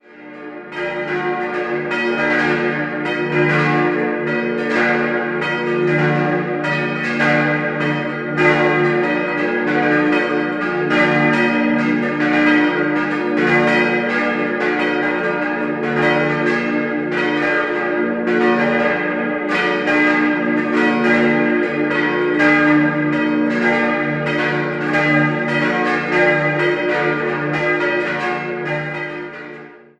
Der heutige Bau wurde im Jahr 1761 geweiht, wobei nach einem Brand im Jahr 1876 eine Umgestaltung stattfand. 5-stimmiges Geläut: dis'-fis'-gis'-h'-d'' Die drei mittleren Glocken wurden 1951 von der Gießerei Bühl gegossen, die kleine stammt noch von 1877, die große von 1995, jeweils aus dem Hause Grassmayr.